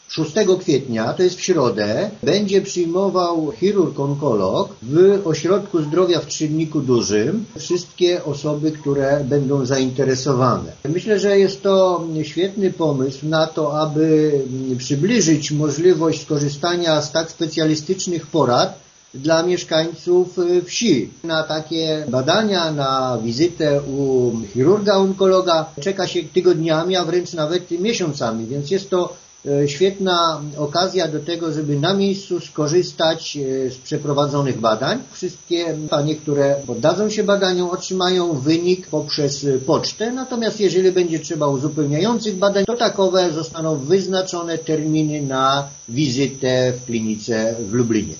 „W ramach akcji odbędą się nie tylko badania, ale także konsultacje lekarskie”– zapowiada wójt Franciszek Kwiecień: